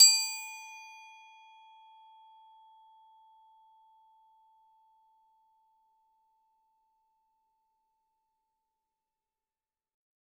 Hard_plastic_f_A3.wav